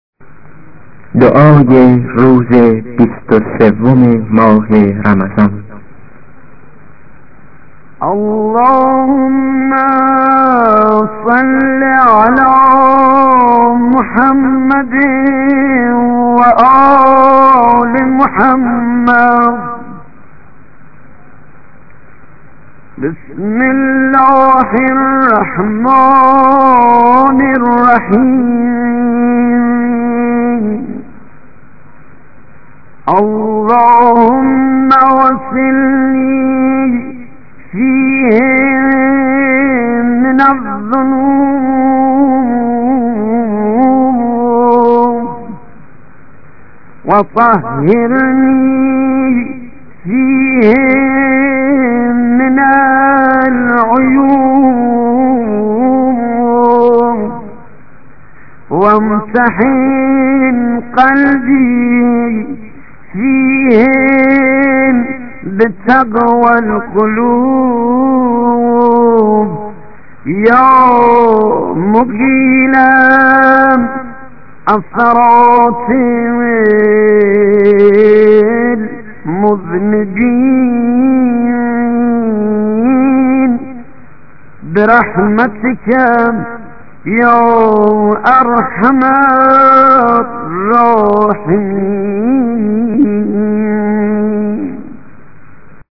ادعية أيام شهر رمضان